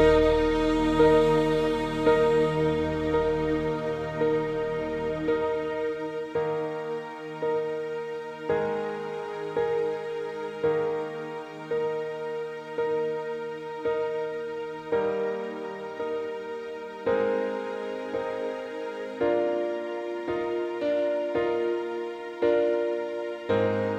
no Backing Vocals Country (Male) 4:51 Buy £1.50